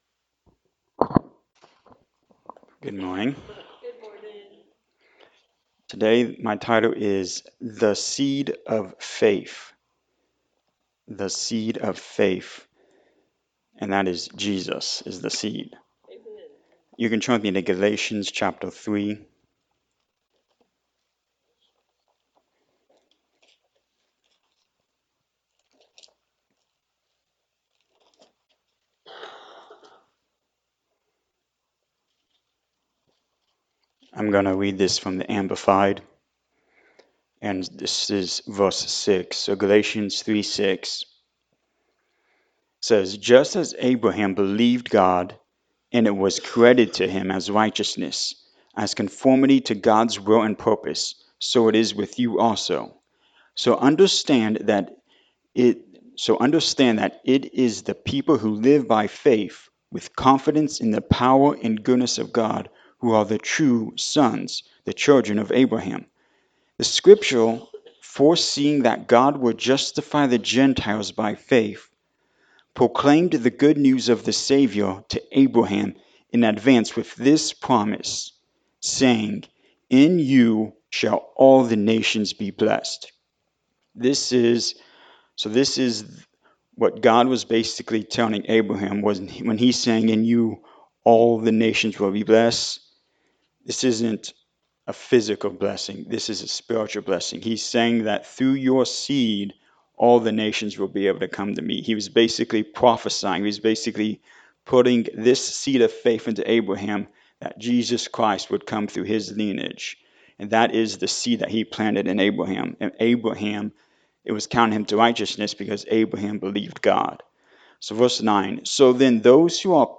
Galatians 3:6-29 Service Type: Sunday Morning Service Jesus is the Seed of Faith.